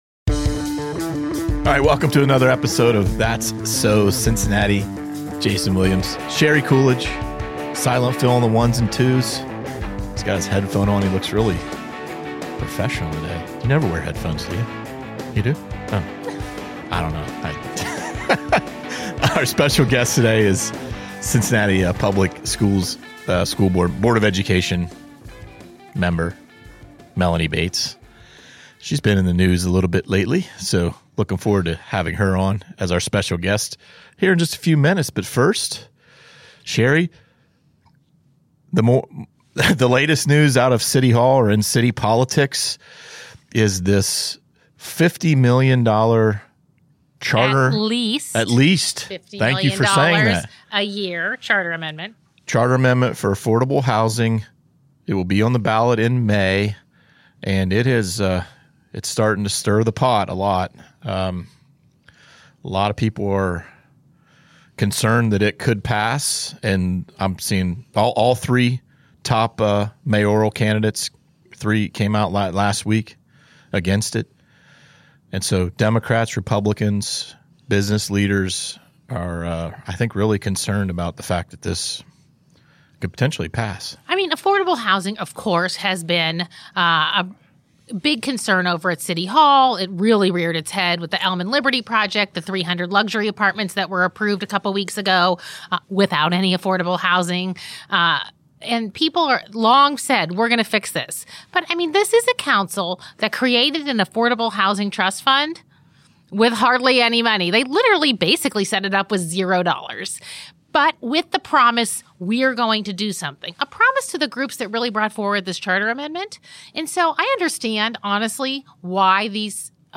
In an interview with The Enquirer's That's So Cincinnati podcast, Bates said students' proficiency results dropped 17% year over year.